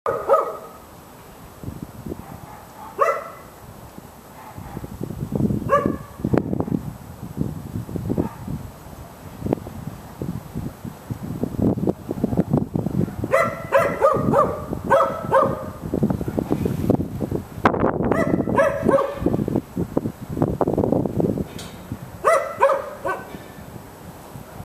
Street Dog Sound Button - Free Download & Play